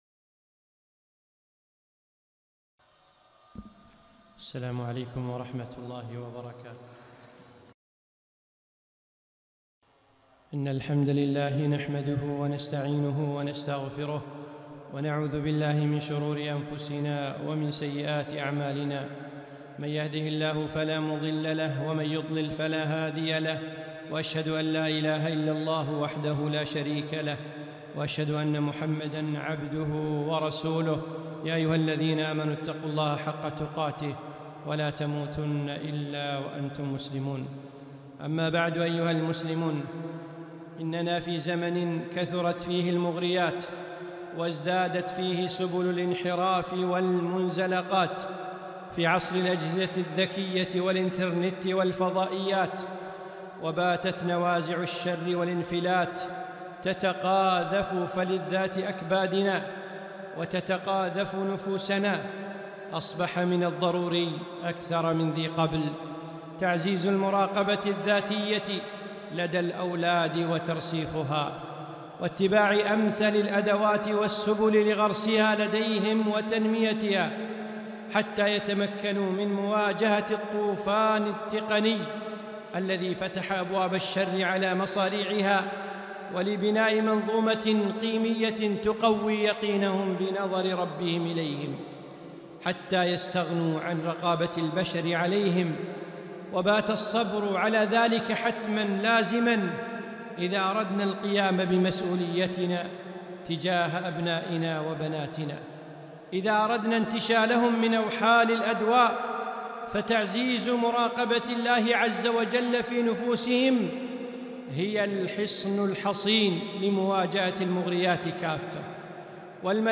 خطبة - تعزيز المراقبة عند الأولاد